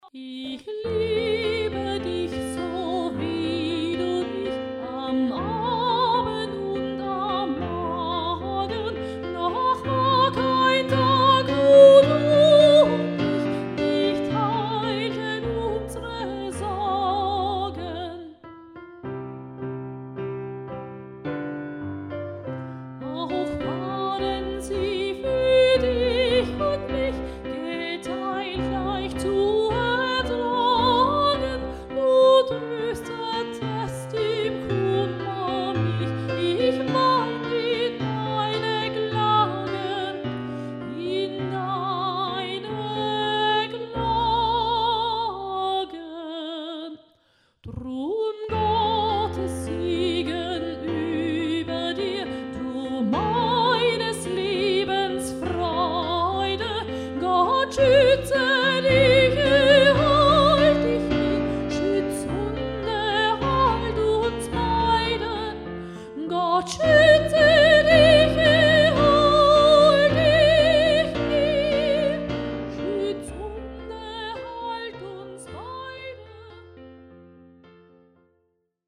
(für die Kirche)